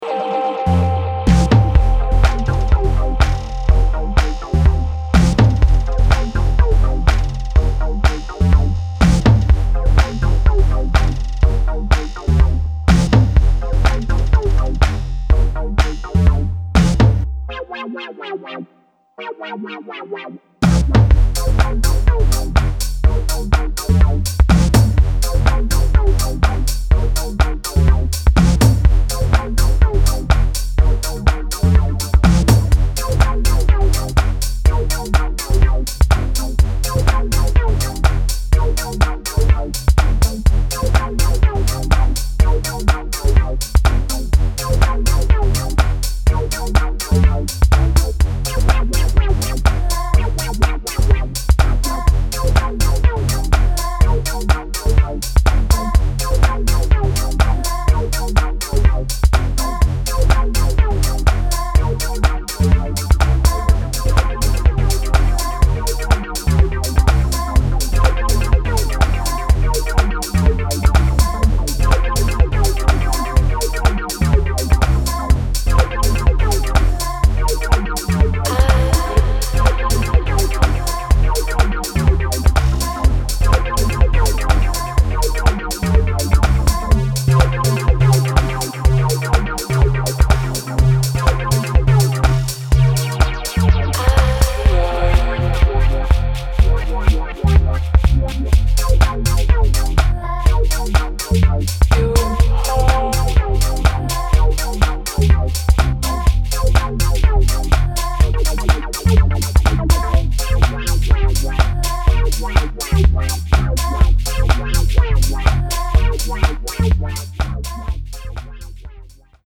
Some more excellent modern house tracks from the north!